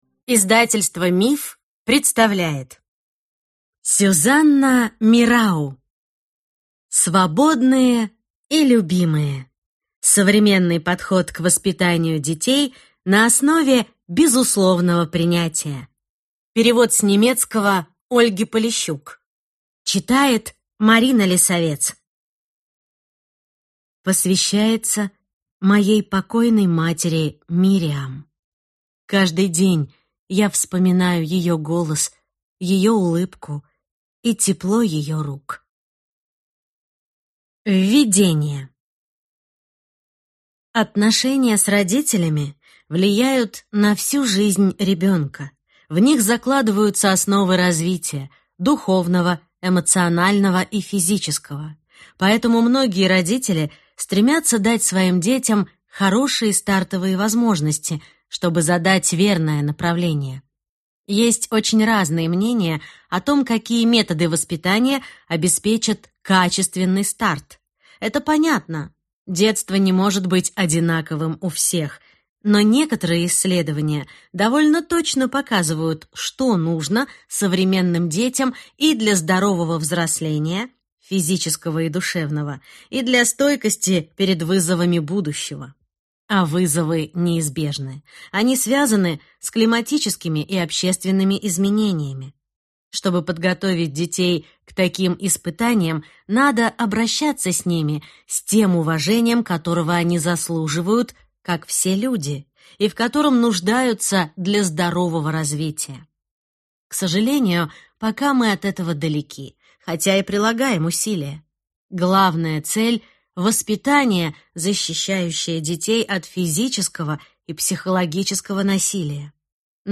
Аудиокнига Свободные и любимые. Современный подход к воспитанию детей на основе безусловного принятия | Библиотека аудиокниг